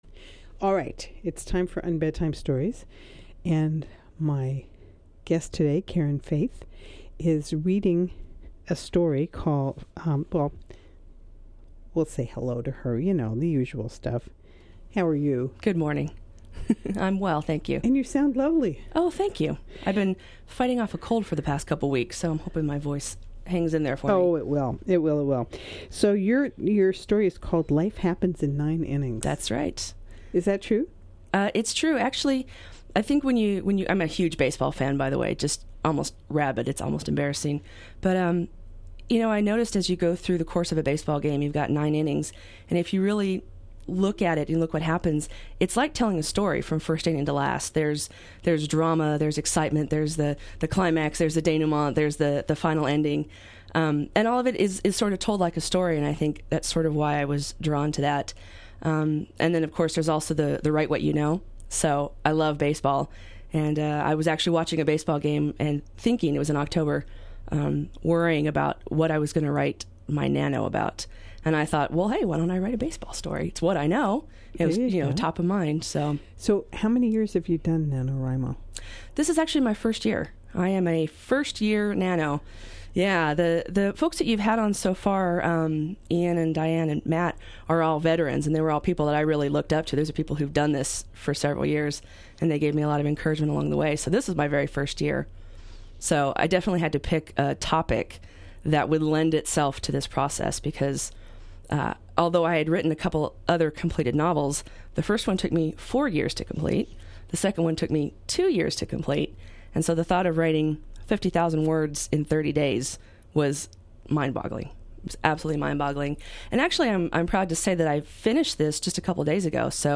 Bay Area radio station KFJC